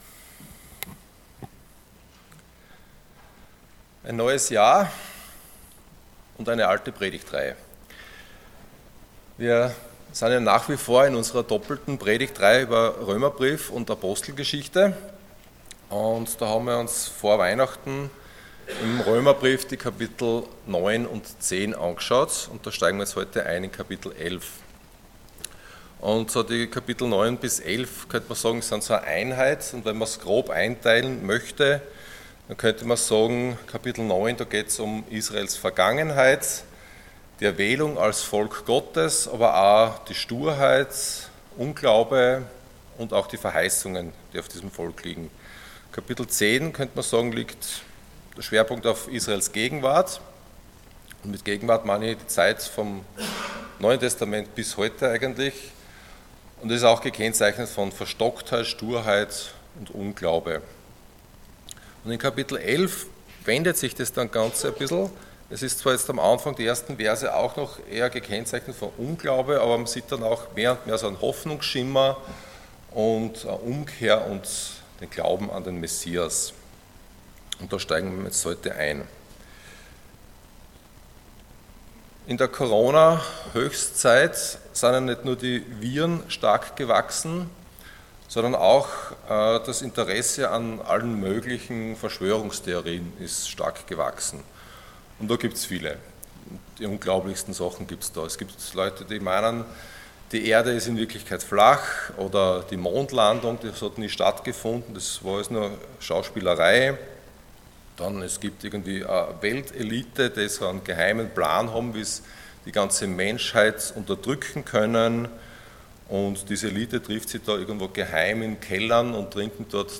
Prediger
Passage: Romans 11:1-10 Dienstart: Sonntag Morgen